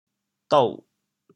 dou6.mp3